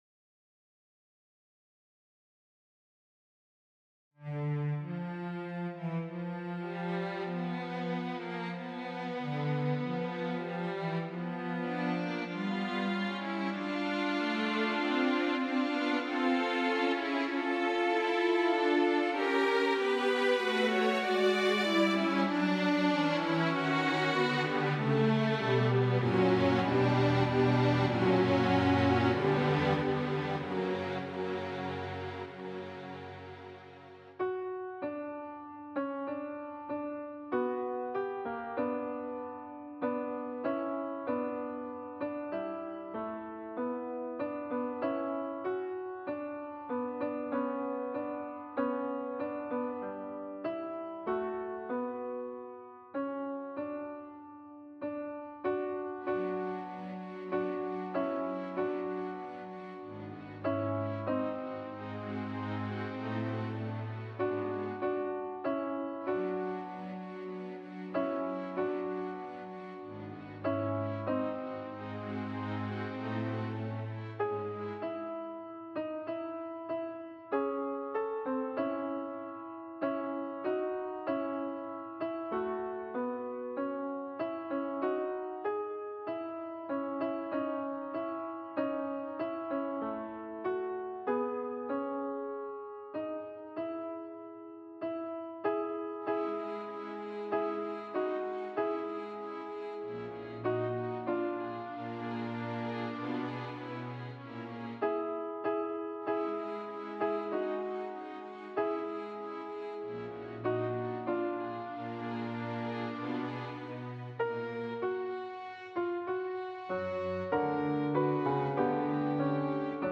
Soprano
Mp3 Música
2.-Offertoire-SOPRANO-Musica.mp3